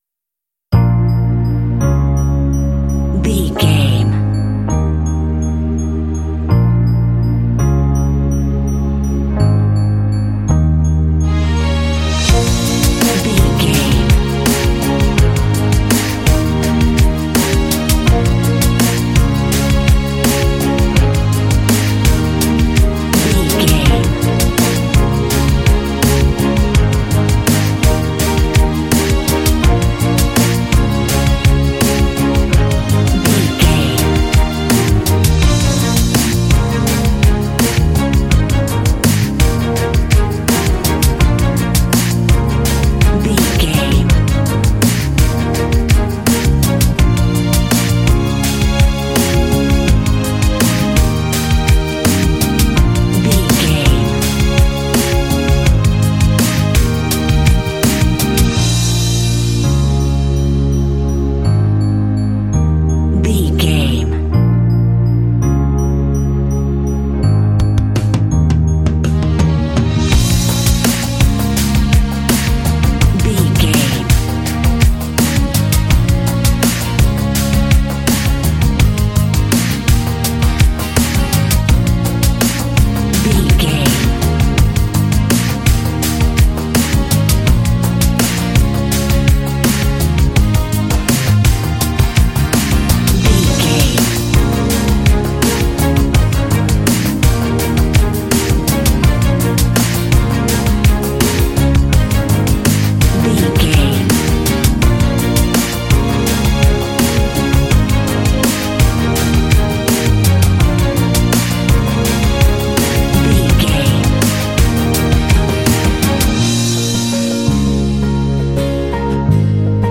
Dorian
B♭
driving
suspense
strings
piano
drums
acoustic guitar
classic rock
alternative rock